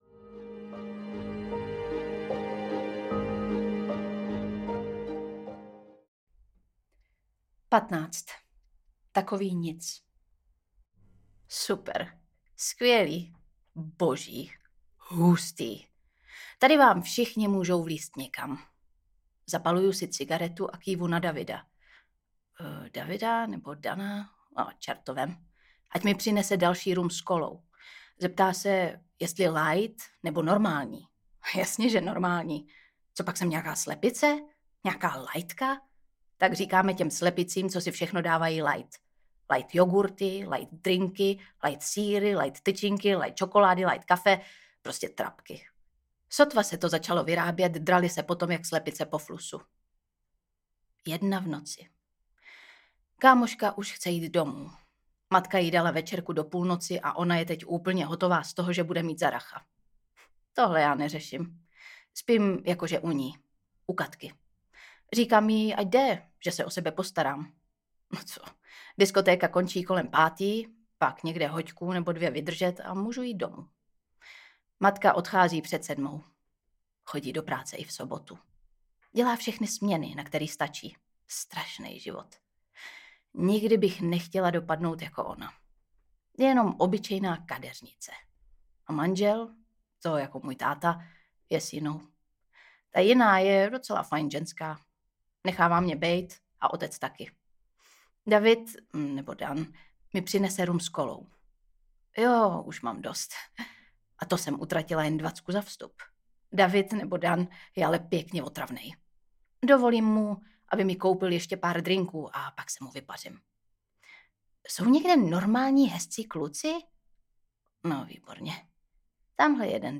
V Tichu audiokniha
Ukázka z knihy
• InterpretLucie Vondráčková